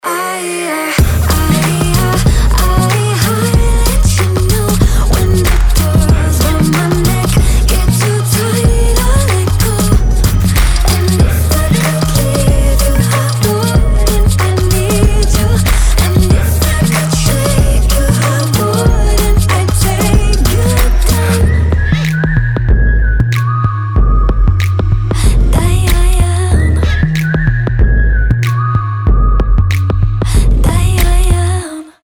поп
красивые
женский вокал
саундтрек